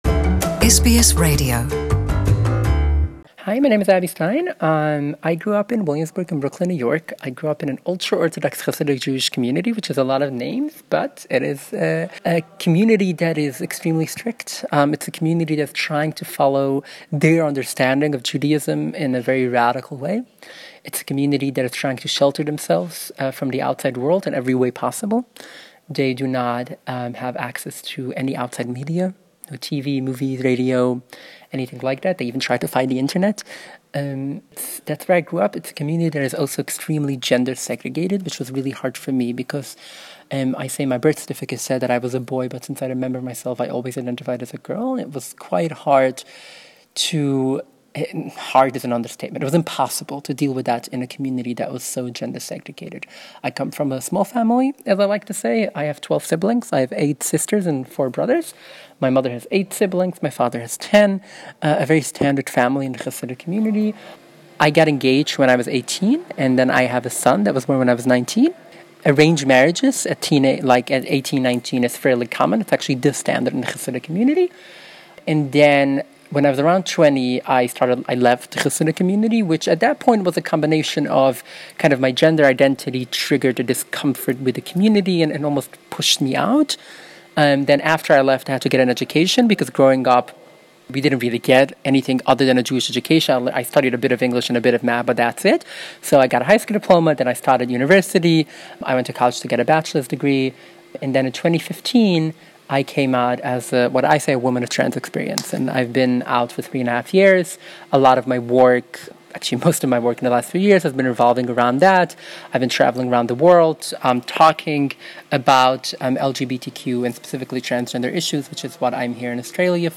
Abby Stein's journey, a transgender woman, raised in a Chassidic ultra-Orthodox community in NY. English Interview
At opening of the 25th World Jewish LGBT+ Congress last week, I met a very special lady, Abby Stein.